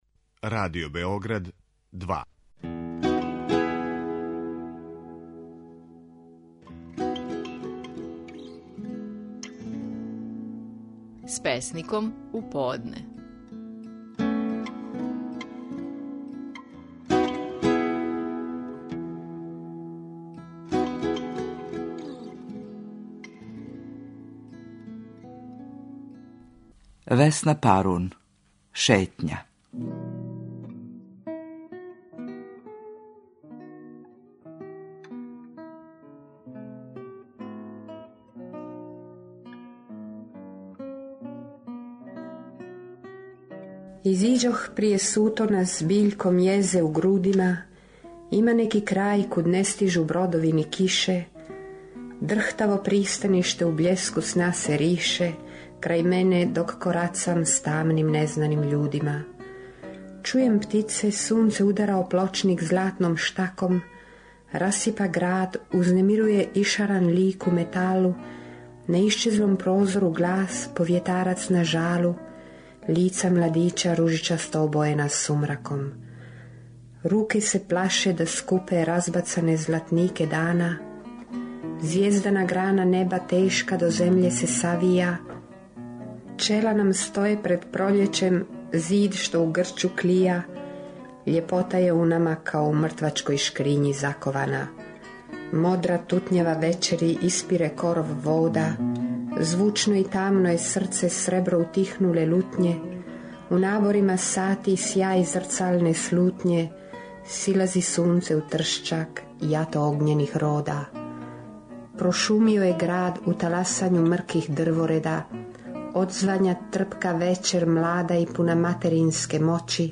Стихови наших најпознатијих песника, у интерпретацији аутора.
Весна Парун говори своју песму „Шетња".